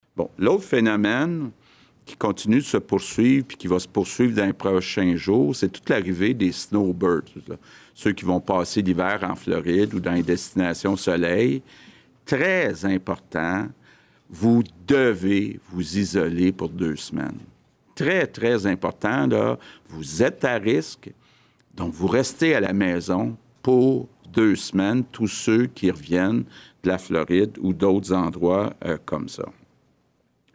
Le premier ministre, François Legault a rappelé que le confinement pour les gens qui reviennent de voyage est maintenant obligatoire :